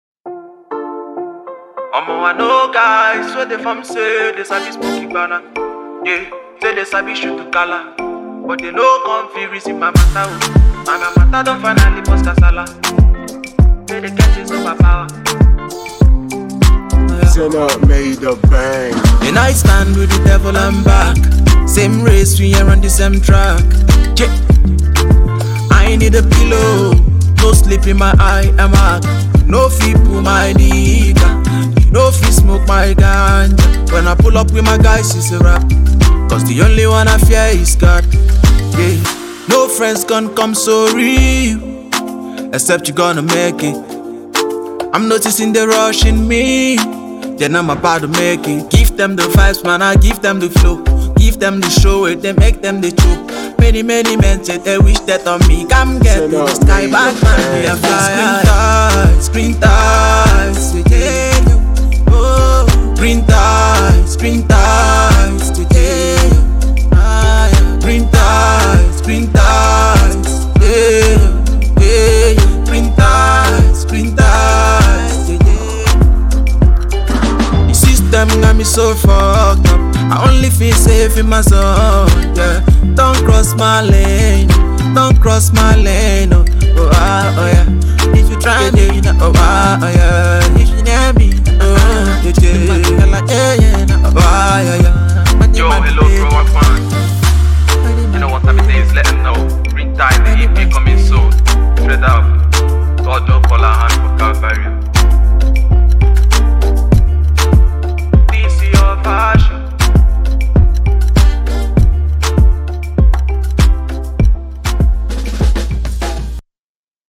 afro fusion
warm up jingle